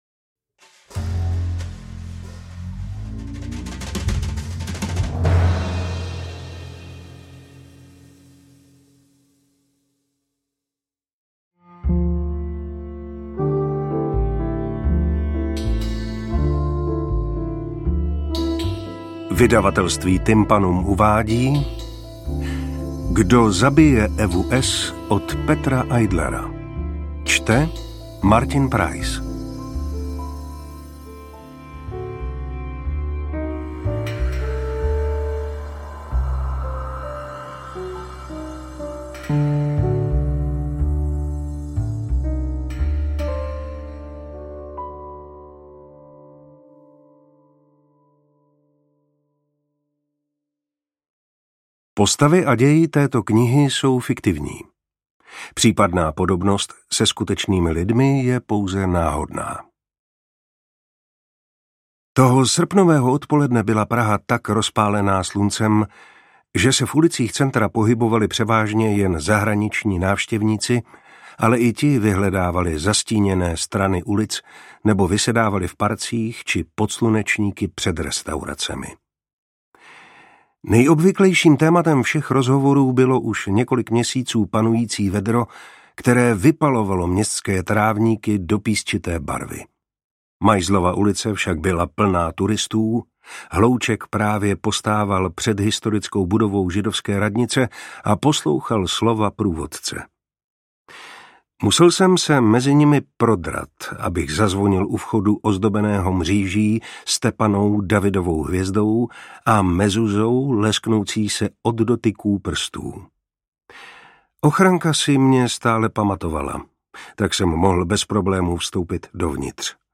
Interpret:  Martin Preiss
detektivky
AudioKniha ke stažení, 34 x mp3, délka 9 hod. 13 min., velikost 507,5 MB, česky